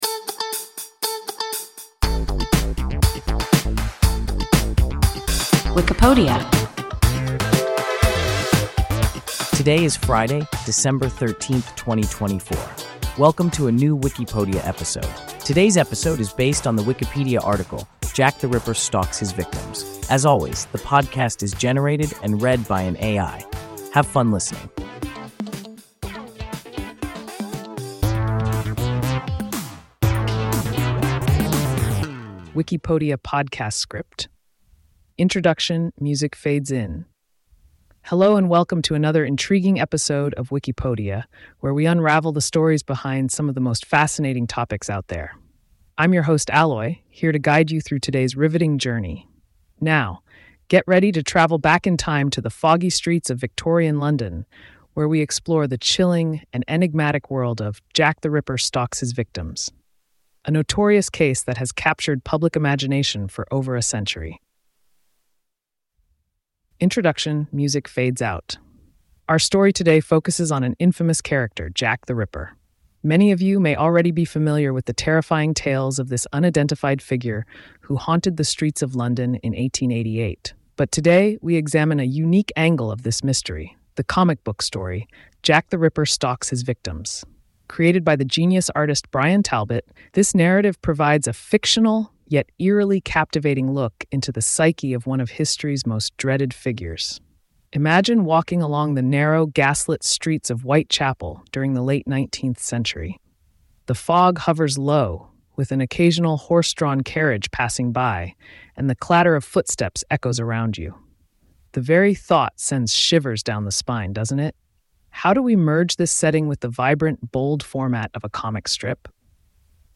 Jack the Ripper Stalks His Victims – WIKIPODIA – ein KI Podcast